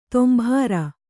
♪ tombhāra